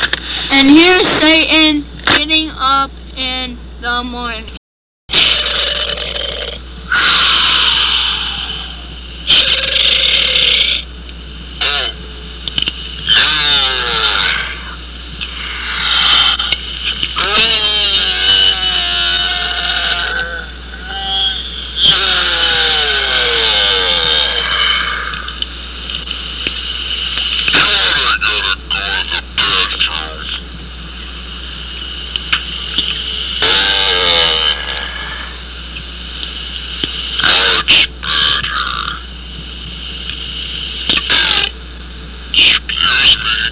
Original dung radio sound bytes! listen to our experimental "satan" trilogy!